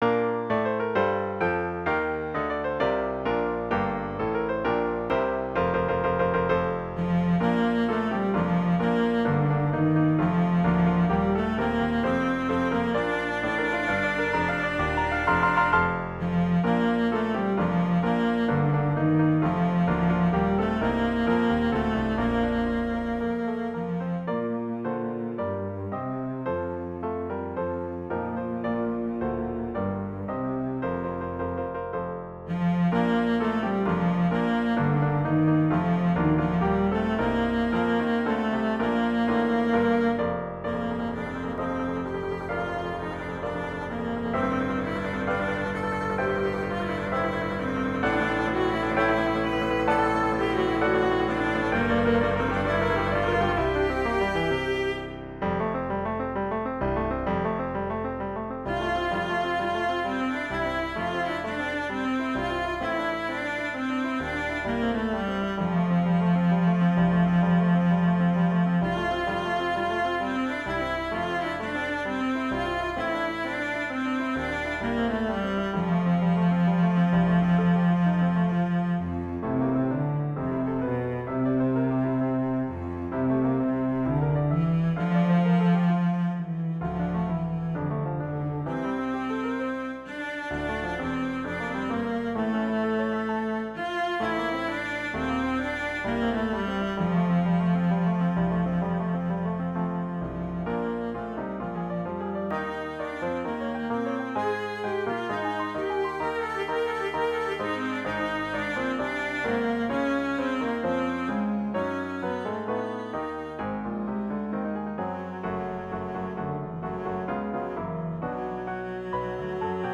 back to the classical style of Haydn, Mozart, and Beethoven